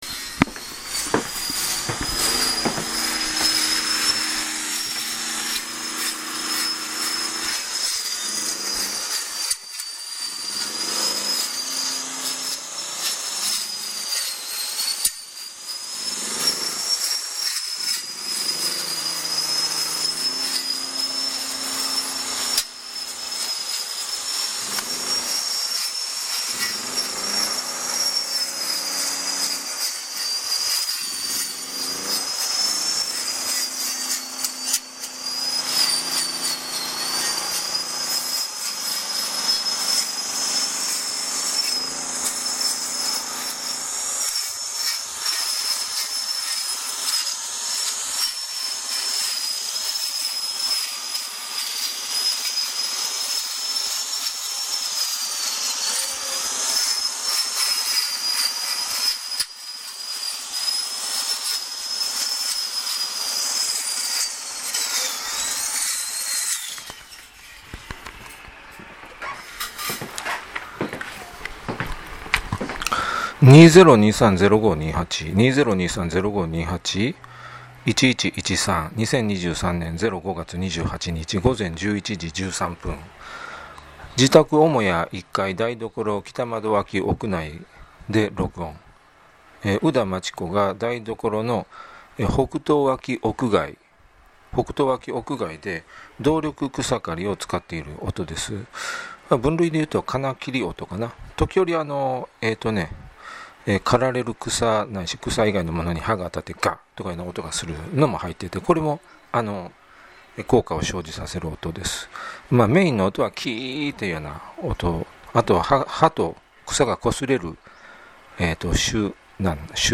< 草刈の刃音 >
キーキーキーンという音。
普通は、これが刃が草に触れる音だが、このケースでは、動力音かもしれない。
草が刃に触れる音は、触れた時だけなので、この音声ファイルの様にずっと鳴りっぱなしにはならない。
歯科医が歯を削る音に似てる。